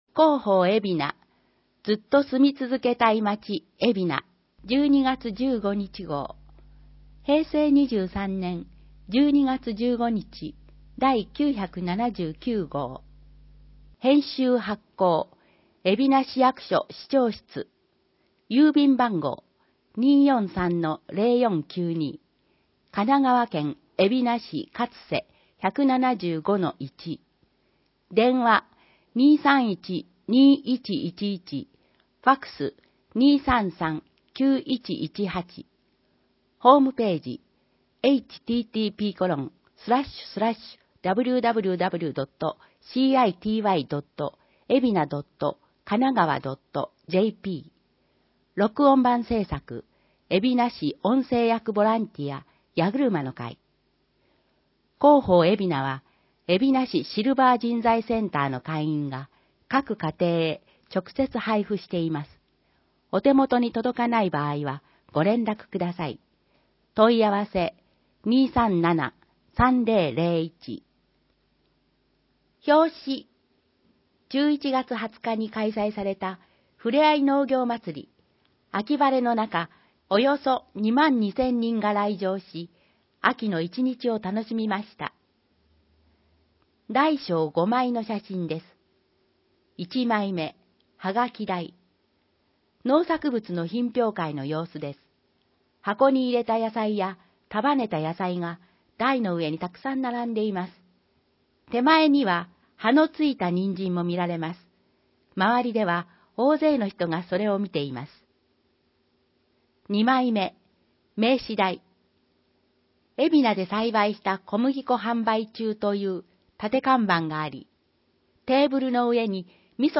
※音声版は、音声訳ボランティア「矢ぐるまの会」の協力により、同会が視覚障がい者の方のために作成したものを、順次登載します。